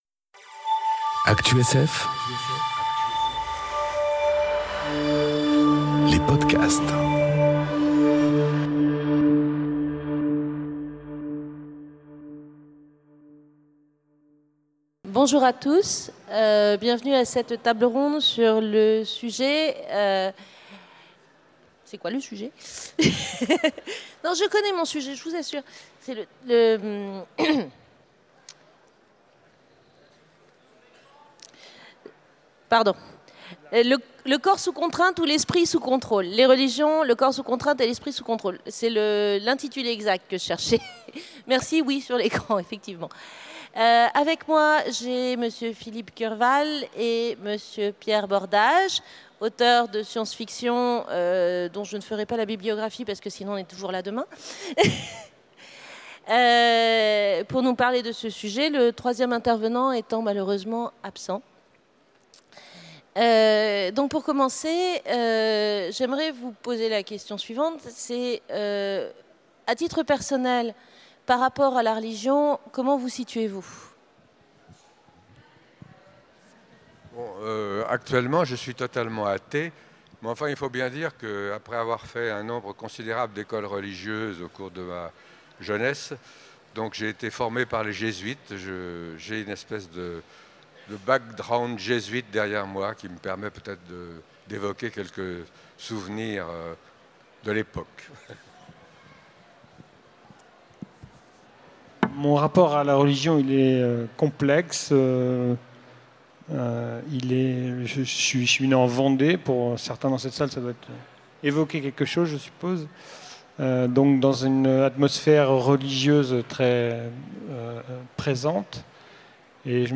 Conférence Religions : le corps sous contrainte ou l'Esprit sous contrôle ? enregistrée aux Utopiales 2018